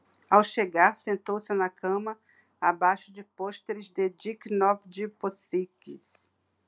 Brazilian_Portuguese_Speech_Data_by_Mobile_Phone